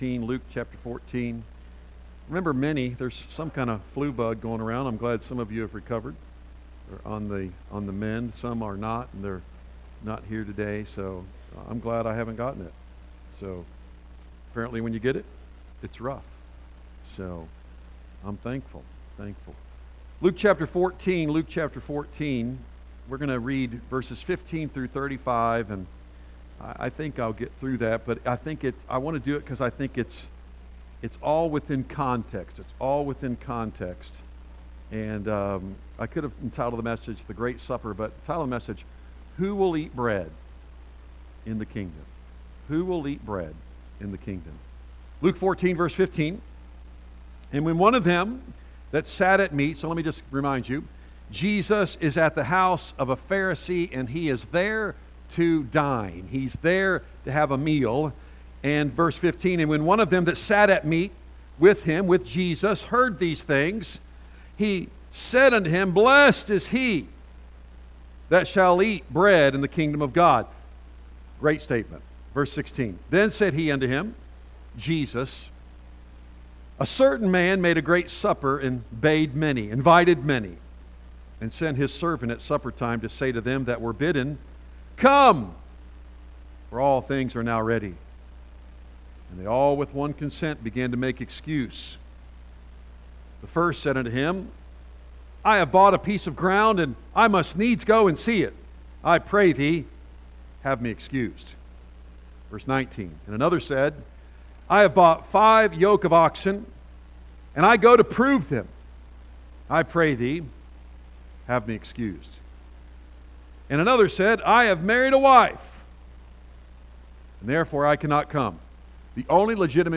A message from the series "Luke."